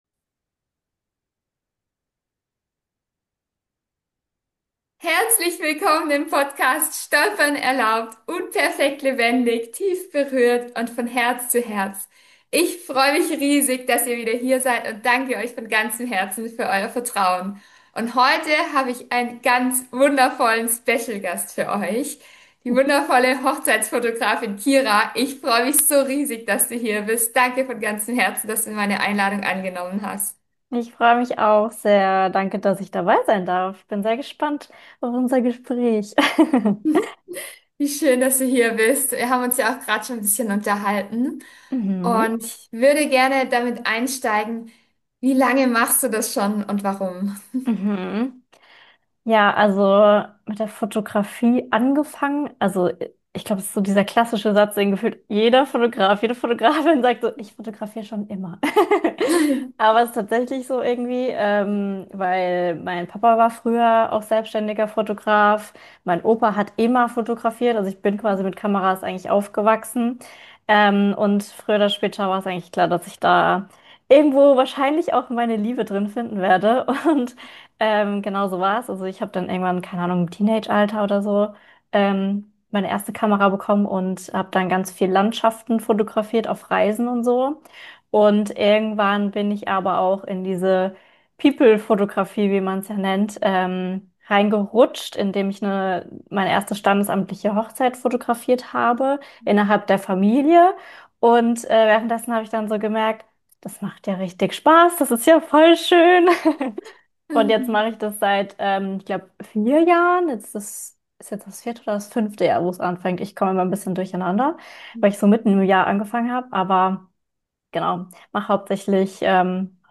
Beschreibung vor 1 Monat Was passiert hinter den Kulissen einer Trauung? Fühlt euch -lich Willkommen in dieser Interview-Folge!